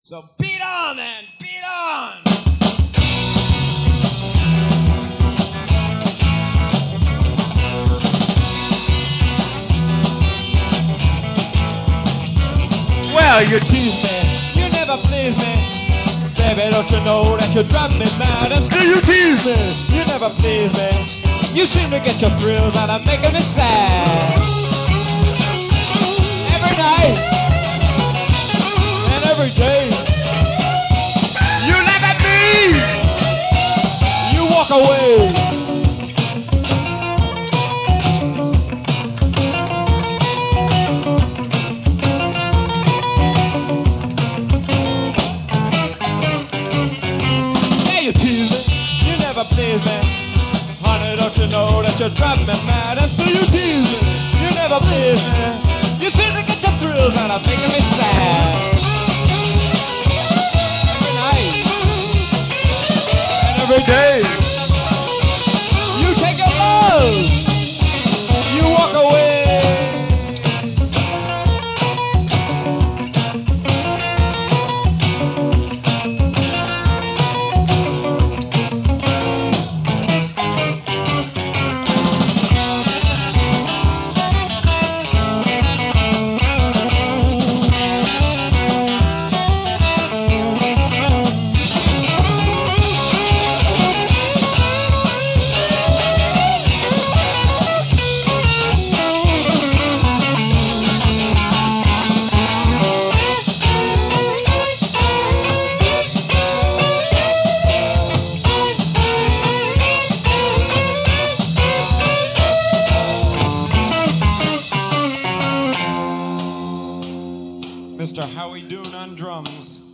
drums
bass---------